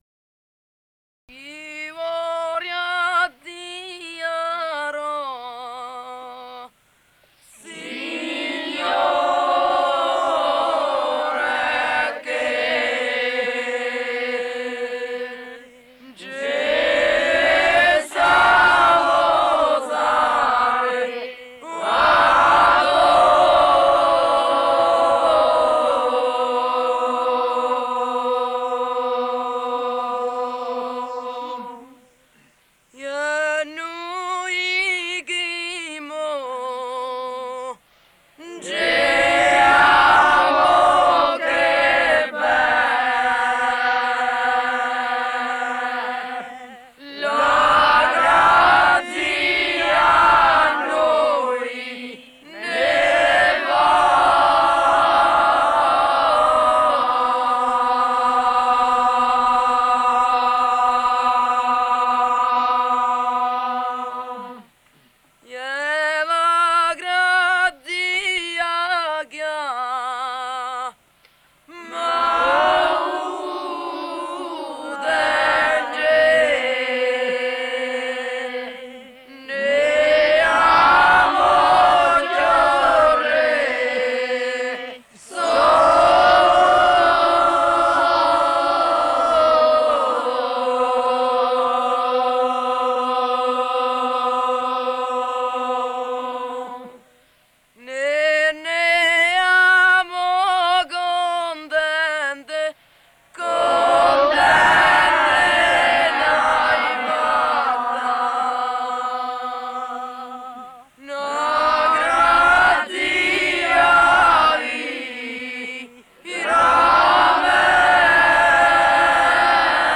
15 - Mixed singers - Song of the Pilgrims to Monte Vergine.mp3